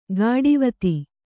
♪ gāḍivati